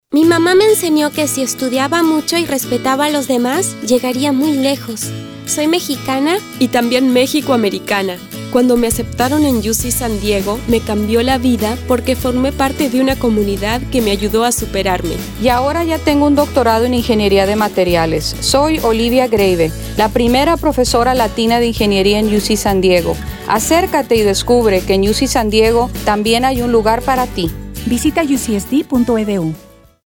Radio Ads
Radio Ad:  There is a place here for you